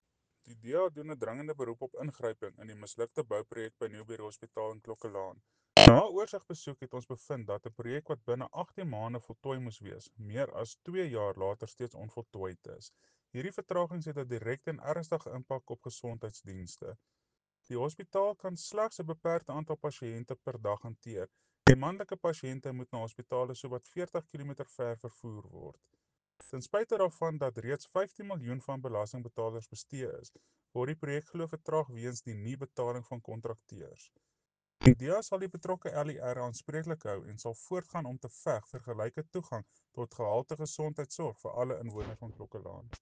Afrikaans soundbite by Cllr Jose Coetzee.